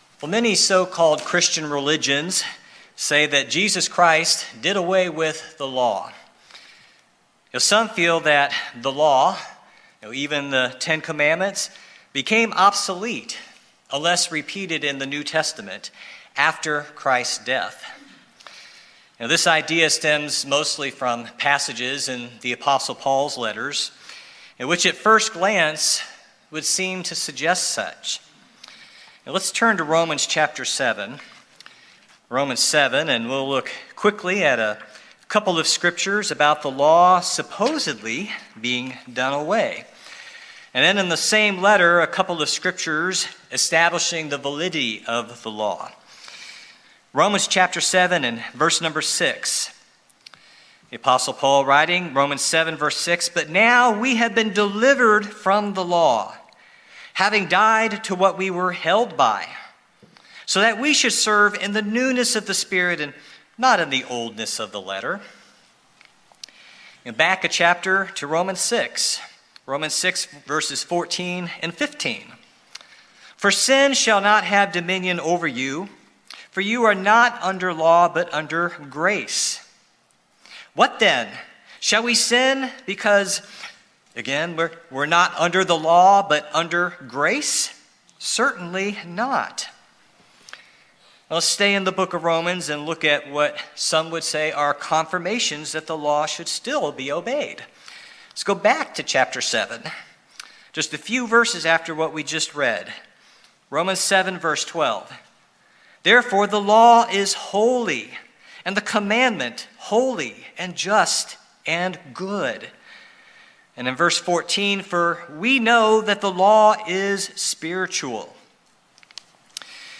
In this sermon we will discuss the various uses of nomos in the New Testament, and what really happened with the Law when the sacrifice of Christ occurred. We’ll talk about how we can distinguish God’s eternal spiritual law, from other types of laws when we read the New Testament scriptures.